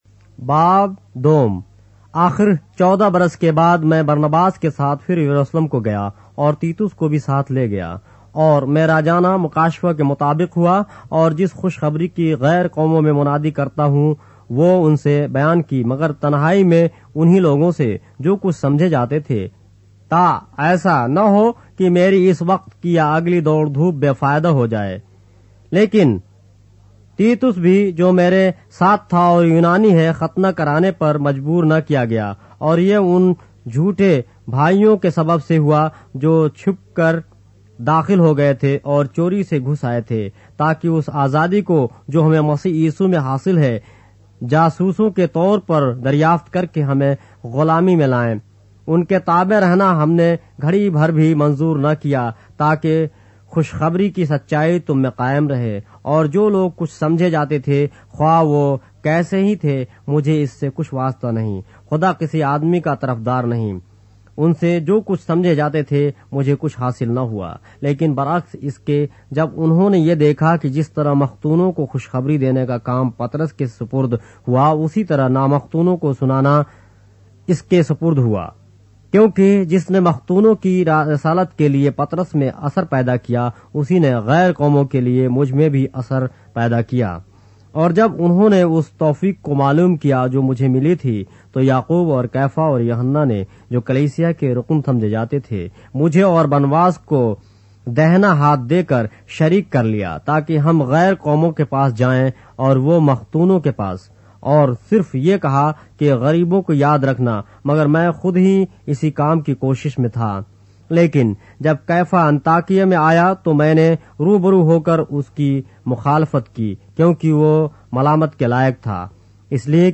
اردو بائبل کے باب - آڈیو روایت کے ساتھ - Galatians, chapter 2 of the Holy Bible in Urdu